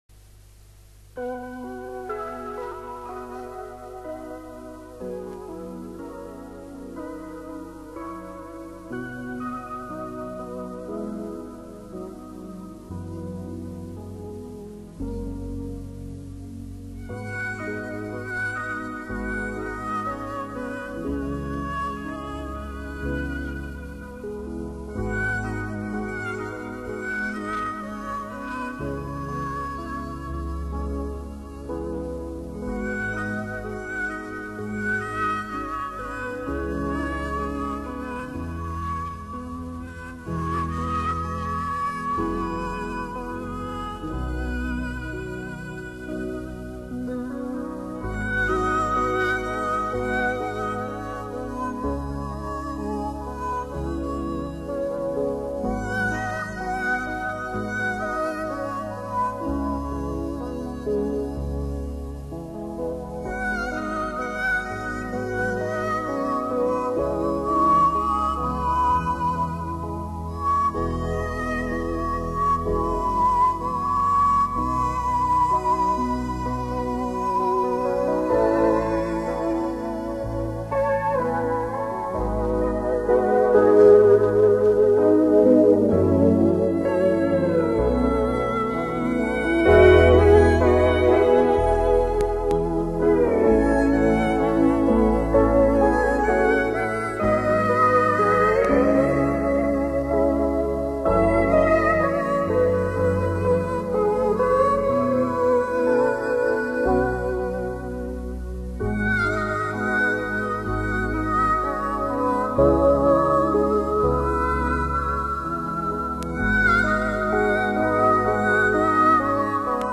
[10/5/2010]求一首口琴曲的名字（有试听）